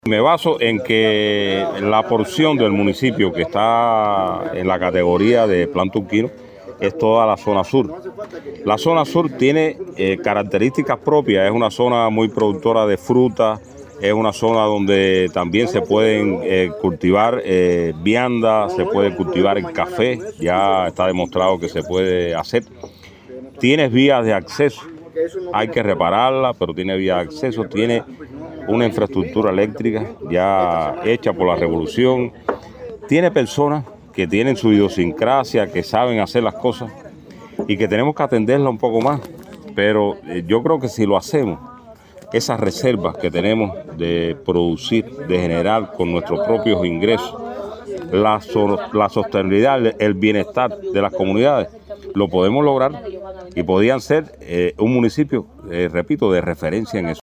Antes de concluir el intercambio, el Secretario de la Comisión Nacional envió un mensaje a la comunidad.